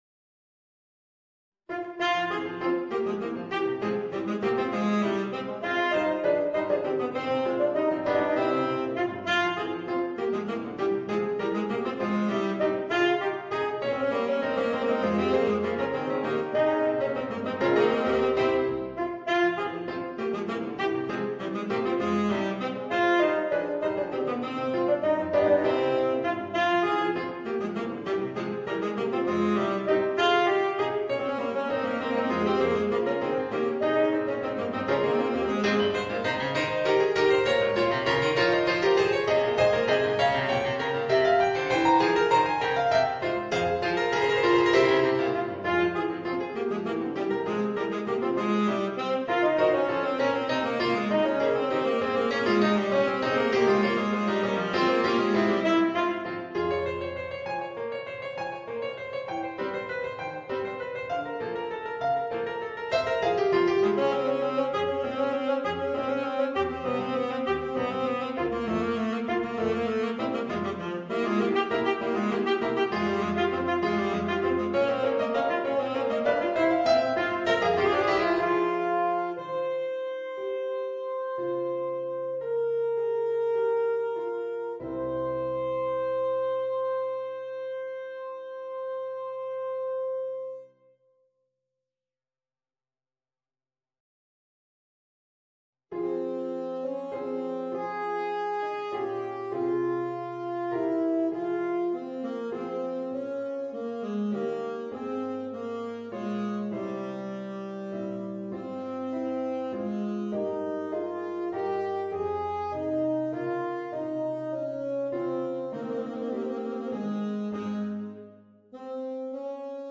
Voicing: Tenor Saxophone and Organ